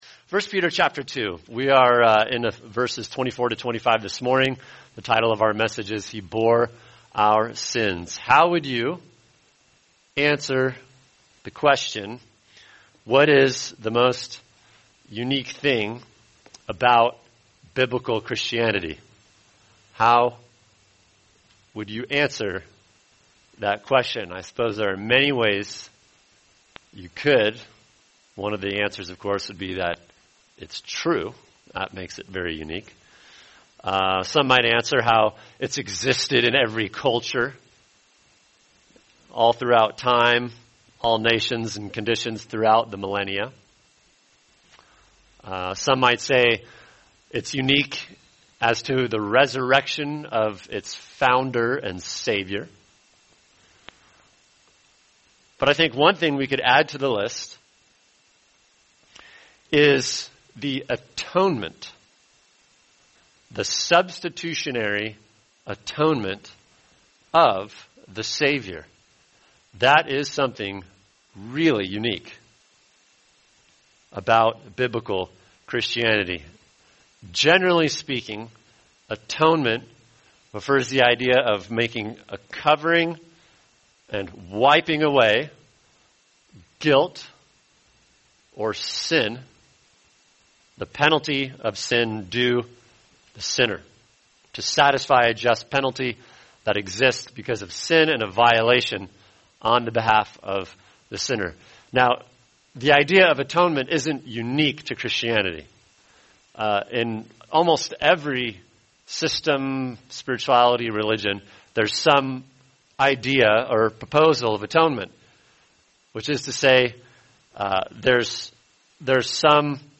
[sermon] 1 Peter 2:24-25 He Bore Our Sins | Cornerstone Church - Jackson Hole